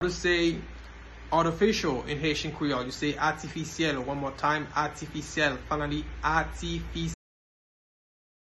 Pronunciation:
Artificial-in-Haitian-Creole-Atifisyel-pronunciation-by-a-Haitian-teacher.mp3